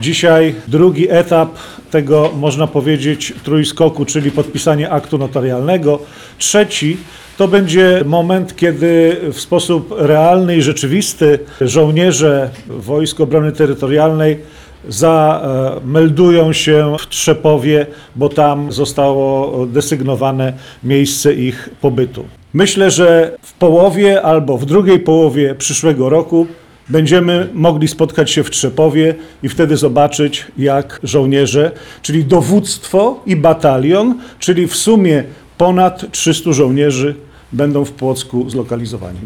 W poniedziałek, 15 grudnia, w Starostwie Powiatowym w Płocku odbyła się konferencja prasowa poświęcona podpisaniu aktu notarialnego dotyczącego obecności jednostki Wojsk Obrony Terytorialnej na terenie powiatu płockiego.
Jak podkreślał Wicemarszałek Sejmu RP Piotr Zgorzelski, wojsko pojawi się w subregionie płockim w 2026 roku.